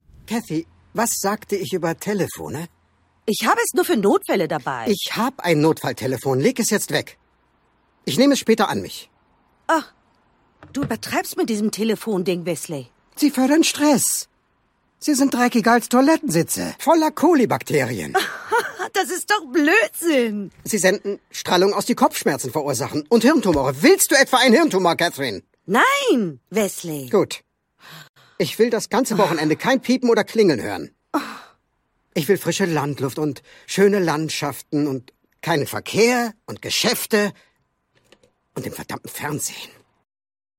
STIMME